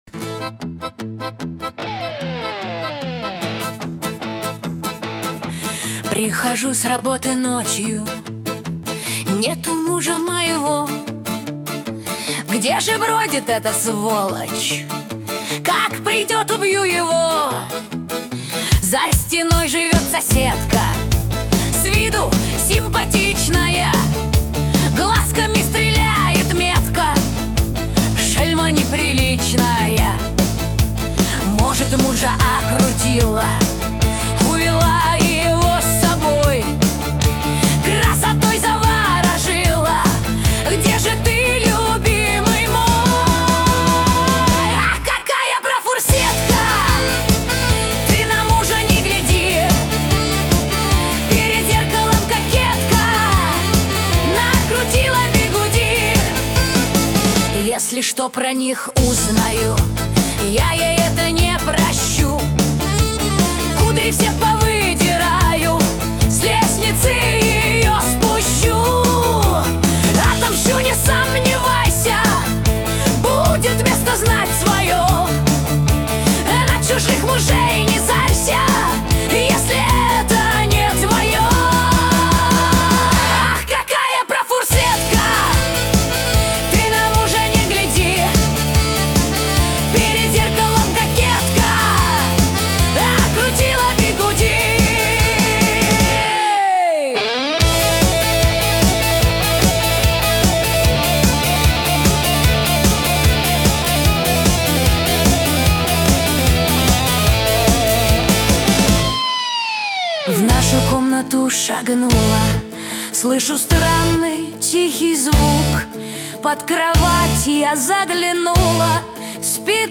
грусть
Шансон